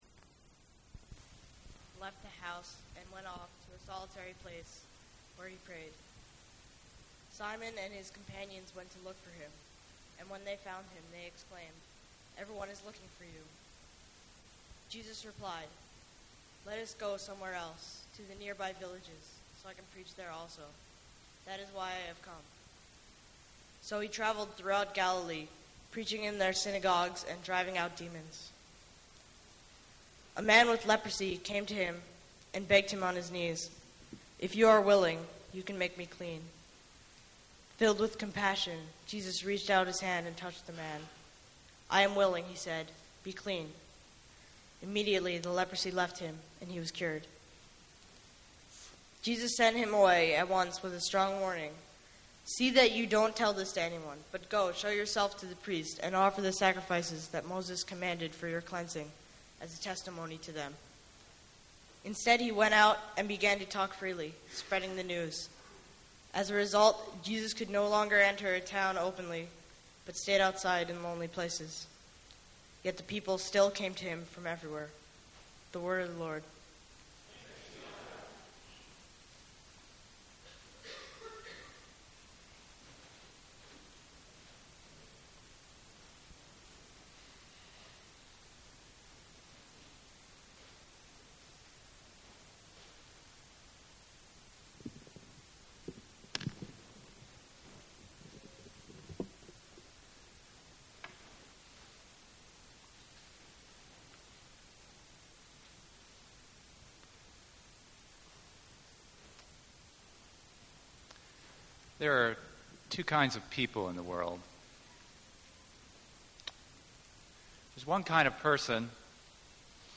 Below are the small group questions we used to engage with the sermon: Which type of person are you more like: a.